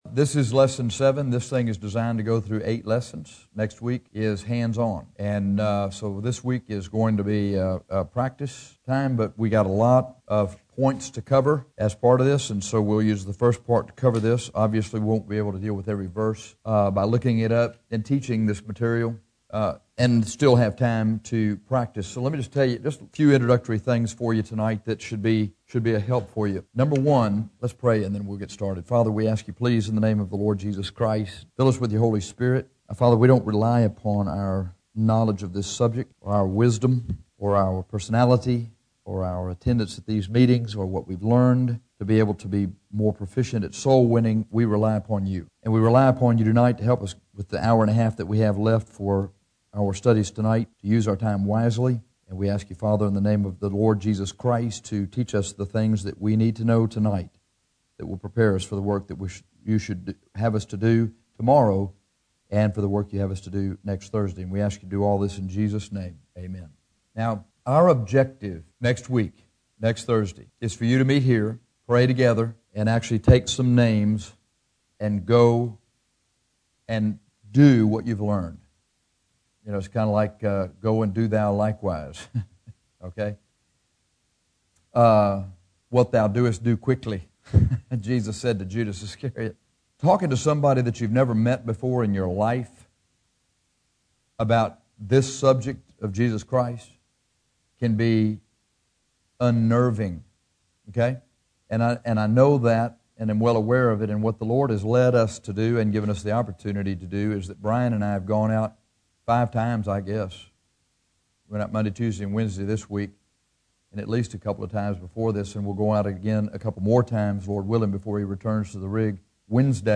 This lesson is designed to give the class participants an opportunity to practice witnessing, “preaching the gospel” and giving their testimonies to each other.